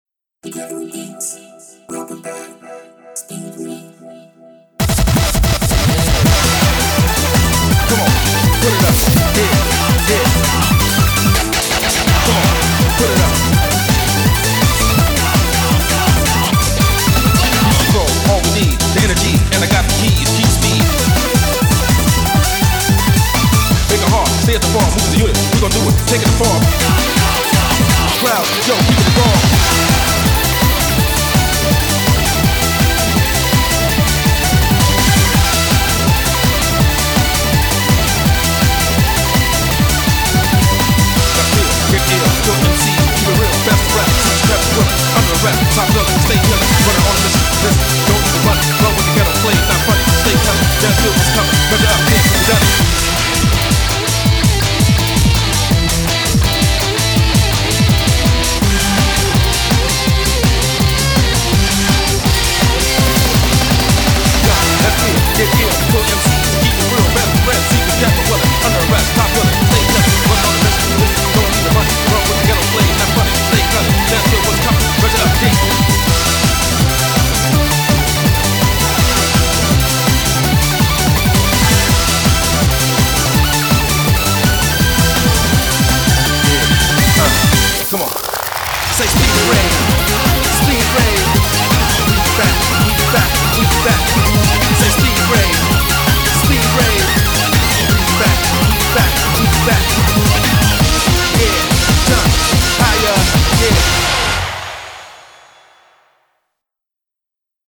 BPM165
Audio QualityPerfect (Low Quality)